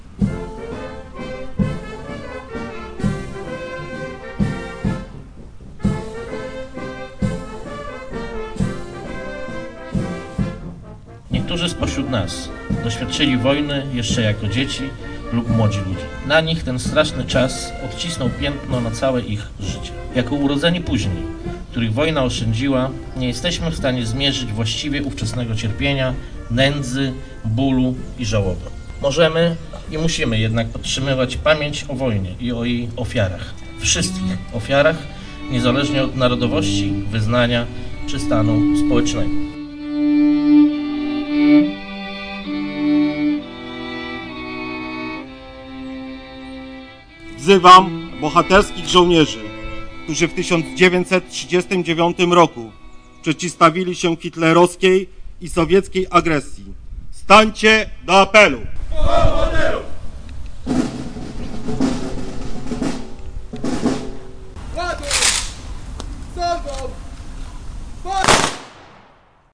Hymn Polski rozbrzmiewał dziś (8.05) w Parku Słowiańskim w Głogowie. Przy Pomniku Koalicji Antyhitlerowskiej obchodzono uroczyście 71. rocznicę zakończenia II Wojny Światowej. Żołnierze oddali salwę honorową, a młodzież przygotowała występ muzyczno-literacki.